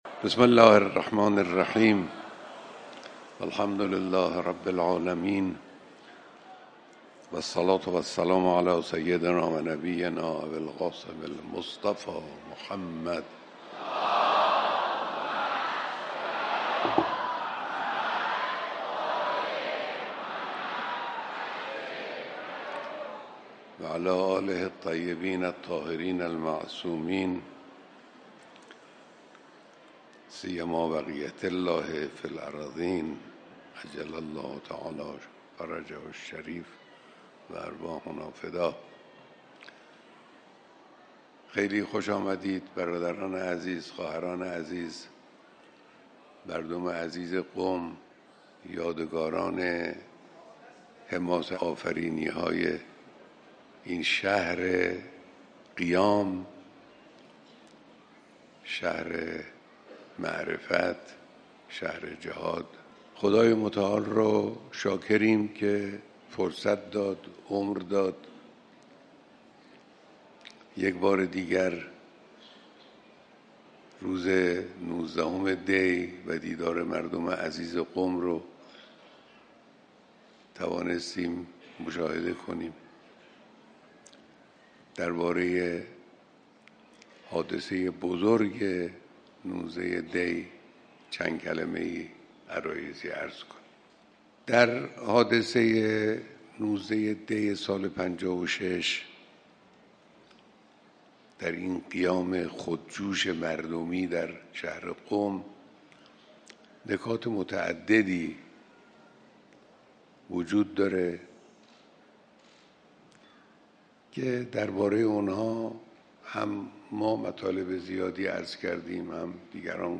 صوت کامل بیانات رهبر انقلاب در دیدار با اقشار مختلف قم